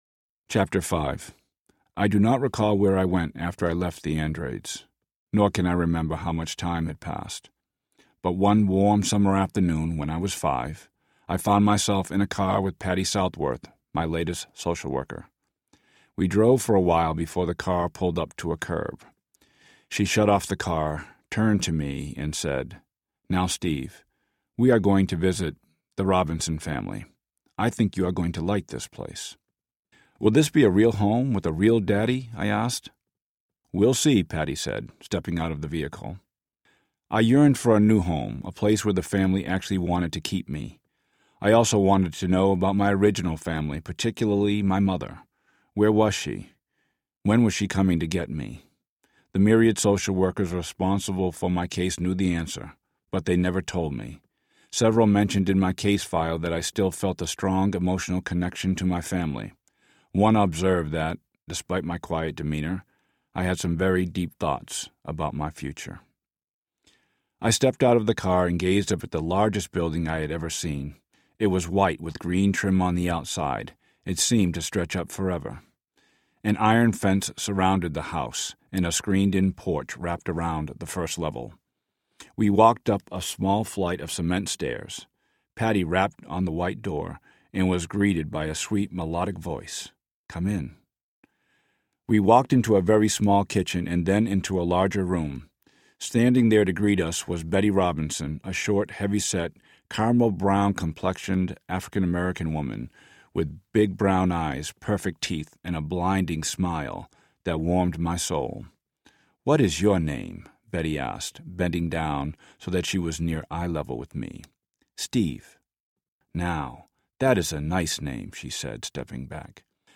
A Chance in the World Audiobook